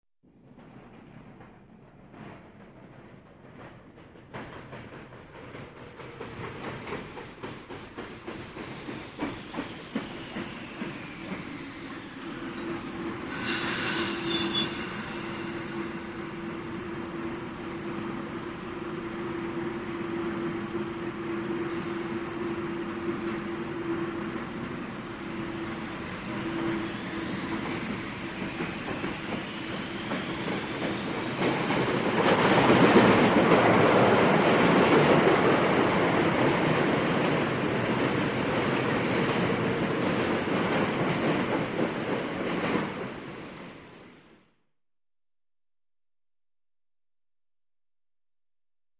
Железная дорога